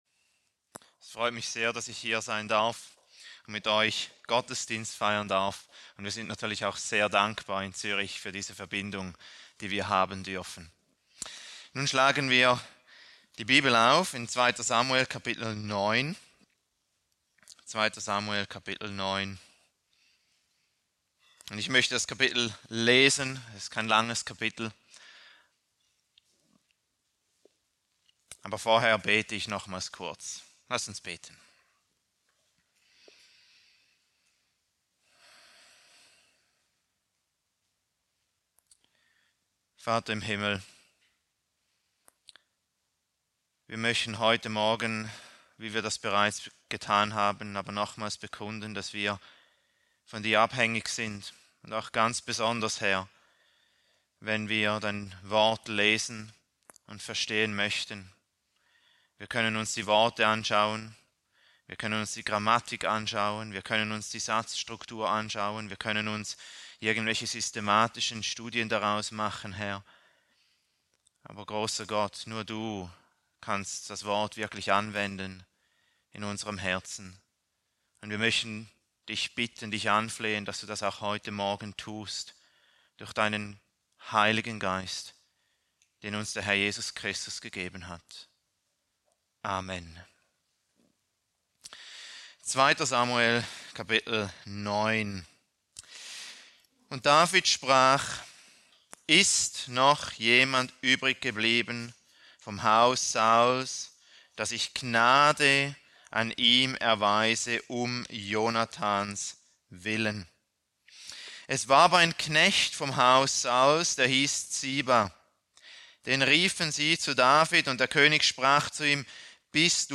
Predigt aus der Serie: "Weitere Predigten"